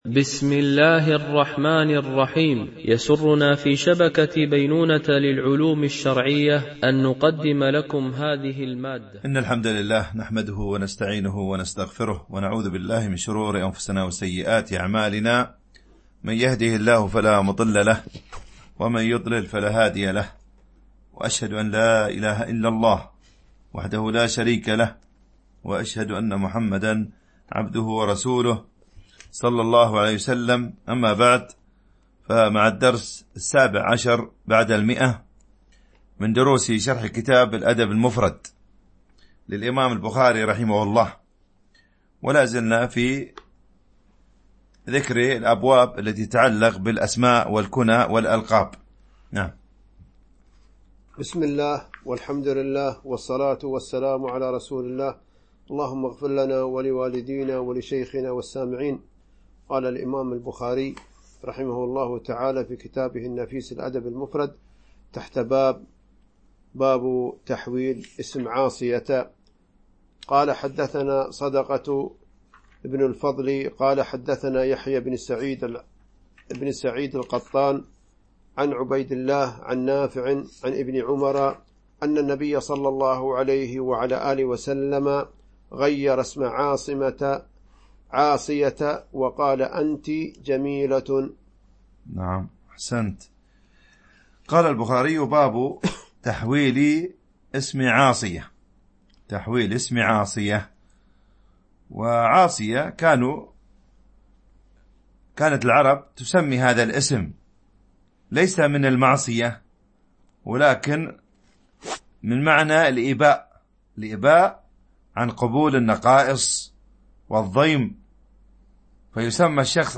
شرح الأدب المفرد للبخاري ـ الدرس 117 ( الحديث 820 – 825 )